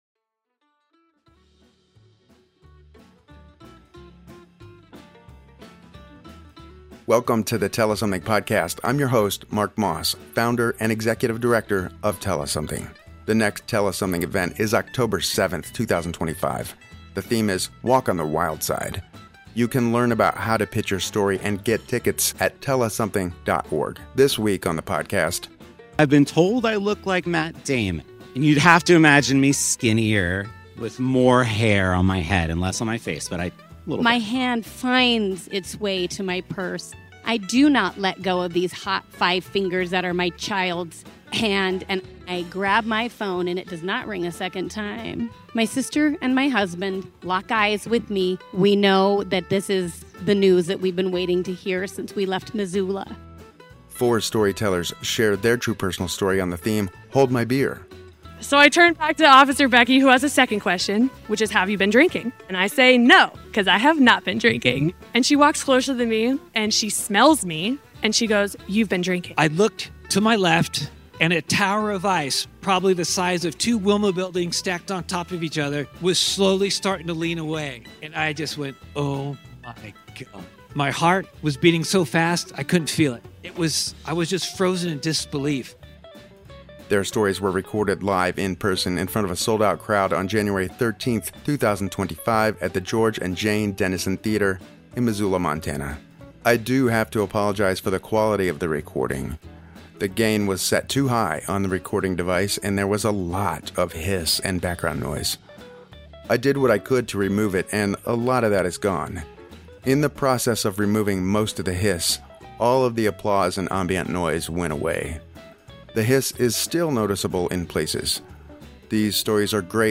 From navigating a life-changing diagnosis in London to a perilous encounter with a glacier in Alaska. Four storytellers share their true personal story on the theme “Hold my Beer”. Their stories were recorded live in-person in front of a sold-out crowd on January 13, 2025, at The George and Jane Dennison Theatre in Missoula, MT.